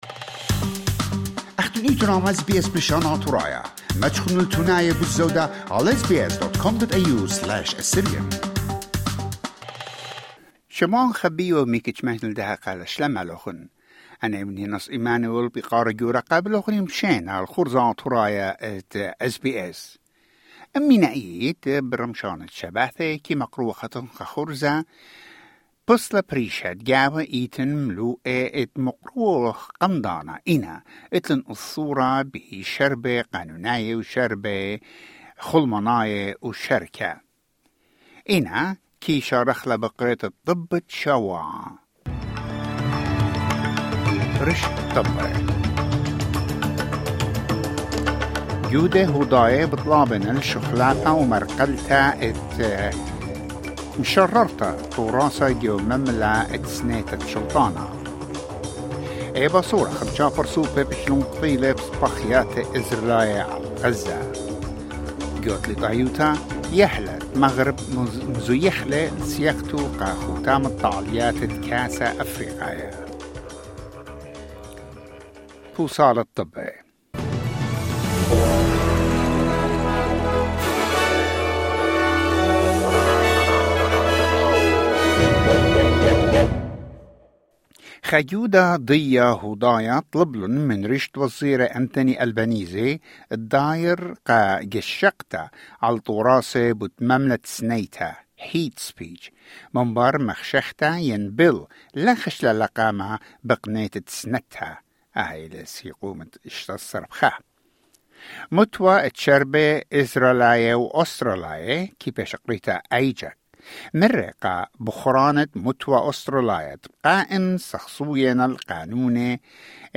Weekly News Wrap in Assyrian